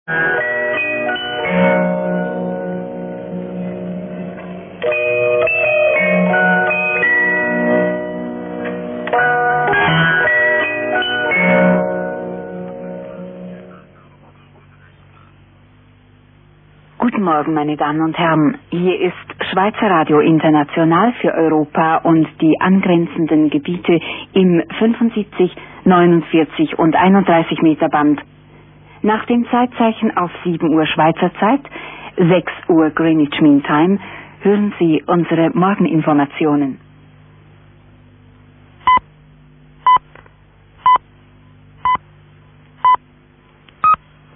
... in Israel mit einem NordMende Globetrotter aufgenommen